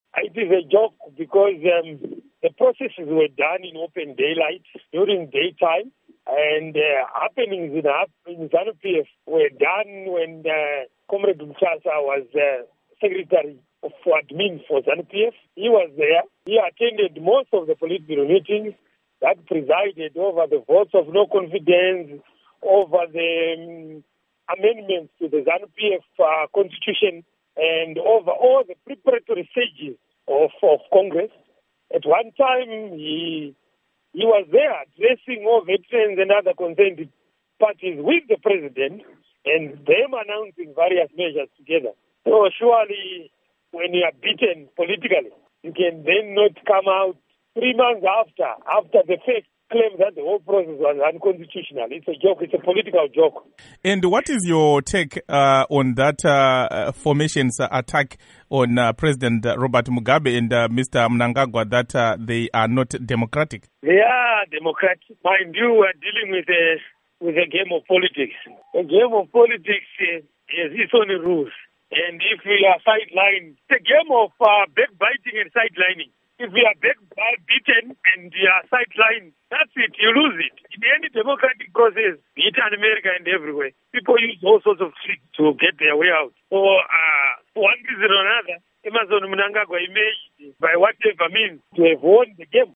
Interview With Provincial Minister Dinha on Zanu PF Conflicts